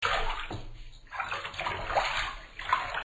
All'inizio ha una funzione solo di suono come una presentazione del brano, mentre subito dopo ho pensato di usare un loop ritmico in 3/4 realizzato con il suono dell'acqua.
Lavaloop.mp3